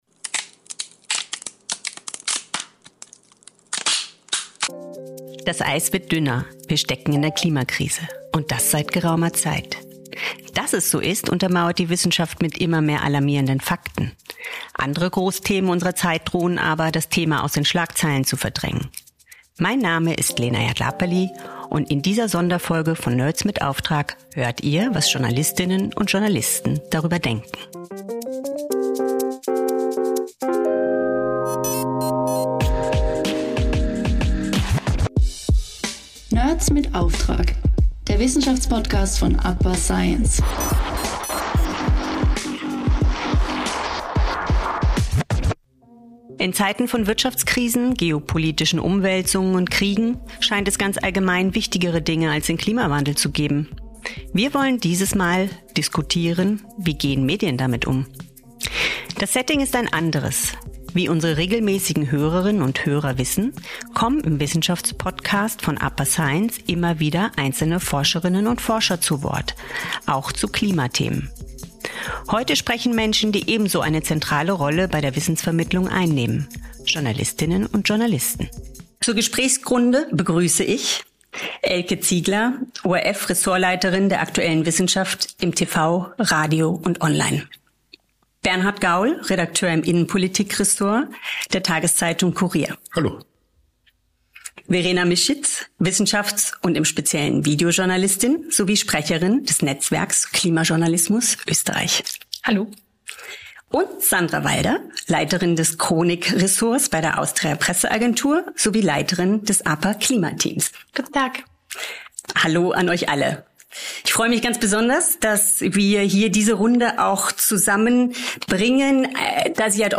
In einer neuen Folge von „Nerds mit Auftrag“ kommen diesmal nicht Forschende, sondern Journalistinnen und Journalisten, die ebenso eine zentrale Rolle bei der Wissensvermittlung einnehmen, zu Wort.